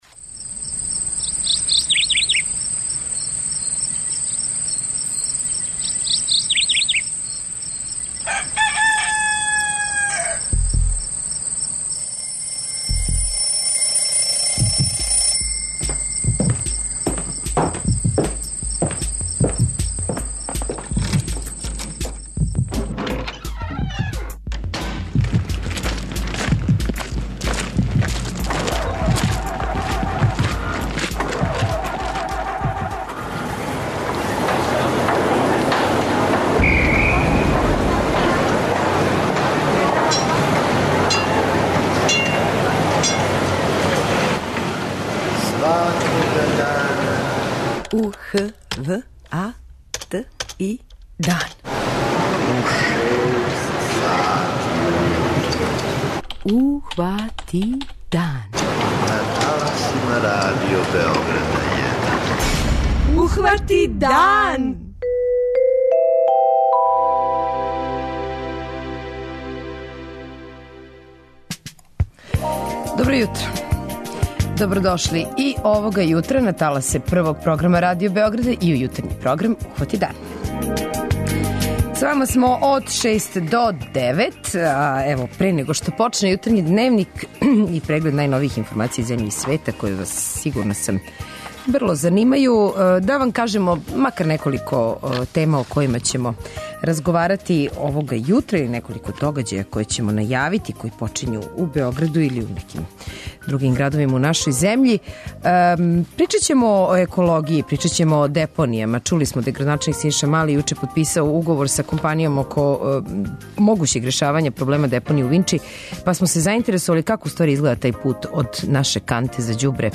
преузми : 85.92 MB Ухвати дан Autor: Група аутора Јутарњи програм Радио Београда 1!